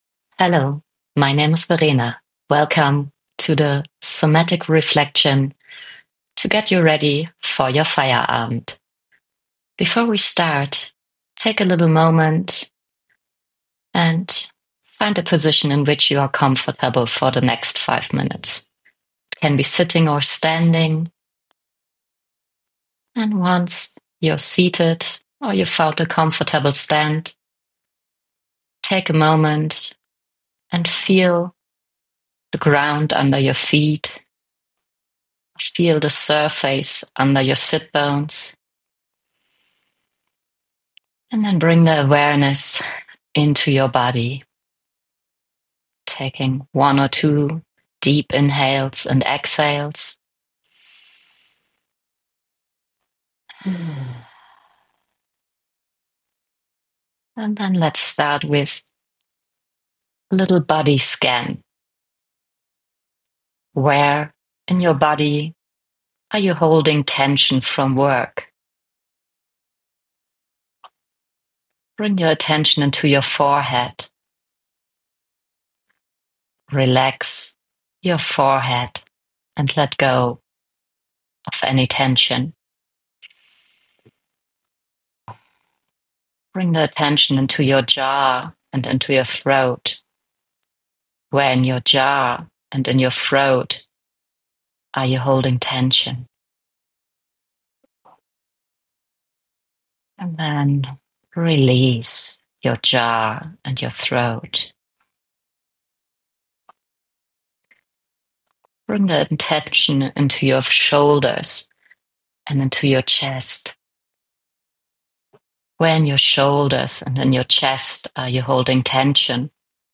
Download the Somatic Reflection Audio Guide to reclaim your Feierabend and leave work stress behind. Enjoy this fully guided embodied practice.